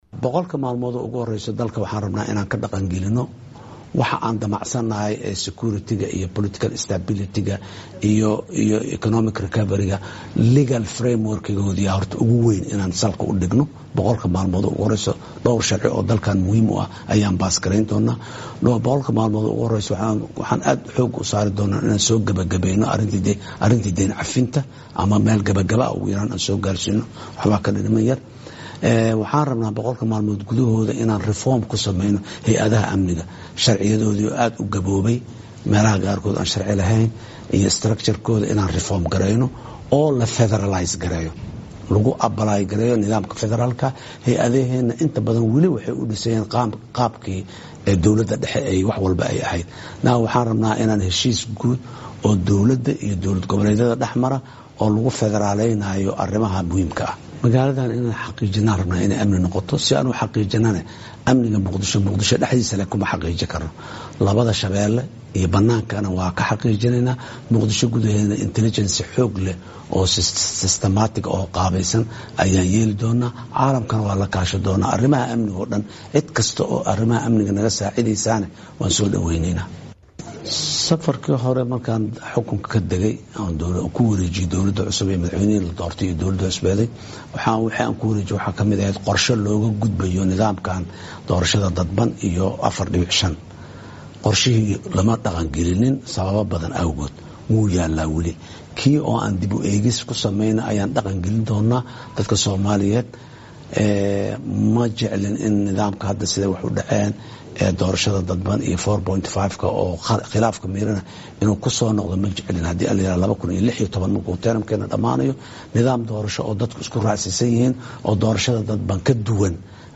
Madaxweynaha oo waraysi bixiyaay ayaa daaha ka qadaay waxyabaha uu qaban doona boqolka malmood ee hooreyo xukunkiisa